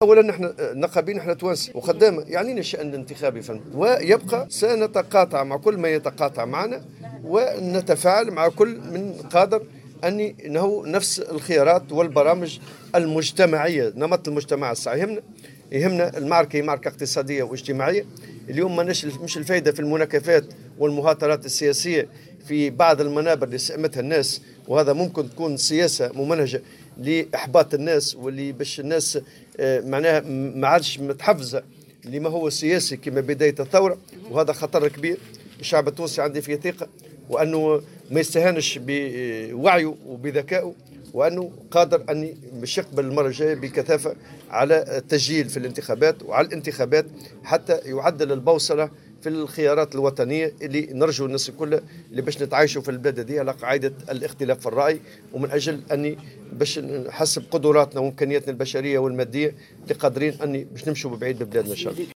وأضاف في تصريح اليوم لـ"الجوهرة أف أم" على هامش زياة أداها الى منزل حياة بولاية المنستير أن المعركة اليوم هي معركة اقتصادية واجتماعية و أن المواطن سئم المهاترات السياسية، مؤكدا أن الإقبال بكثافة على التسجيل في الانتخابات من شأنه أن يساهم في تعديل البوصلة و الذهاب بالبلاد نحو الأفضل، وفق قوله .